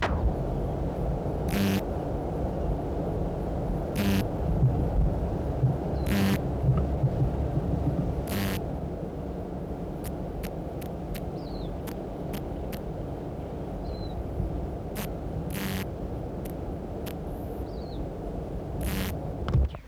Species: Kikihia muta
Reference Signal: 1 kHz at 12 dB at 1m at intervals
Recorder: Uher portable
10_Kikihia_muta_Fox_River.wav